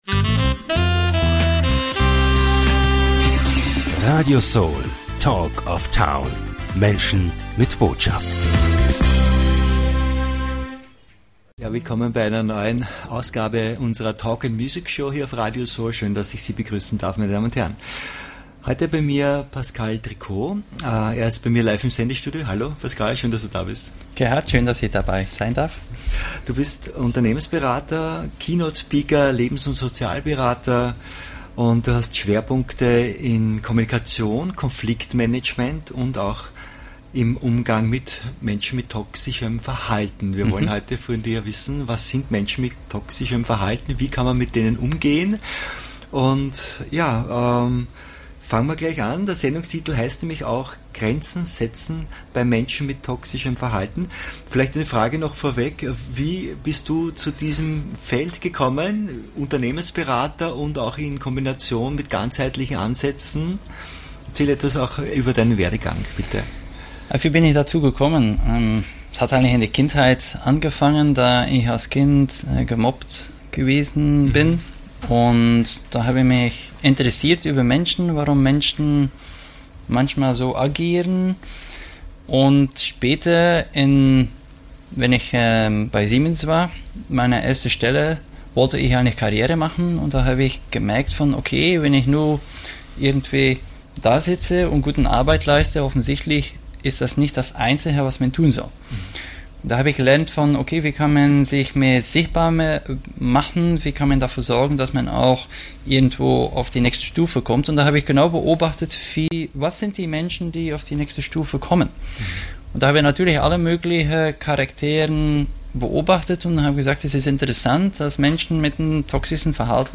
Radio SOL Talk of Town - Menschen mit Botschaft